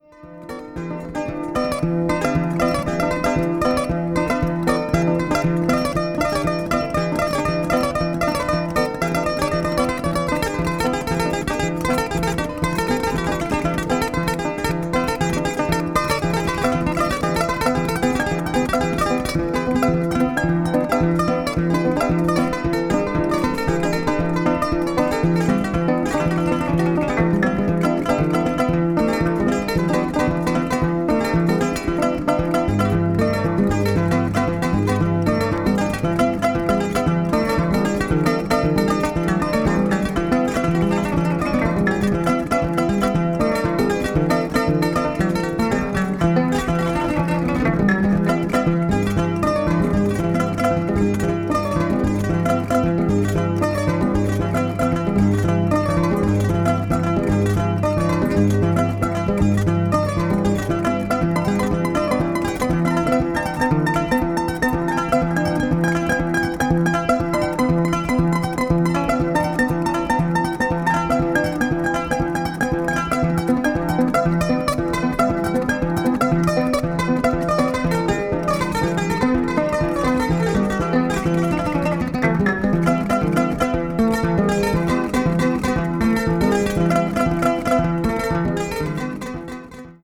africa   folklore   griot   traditional   world music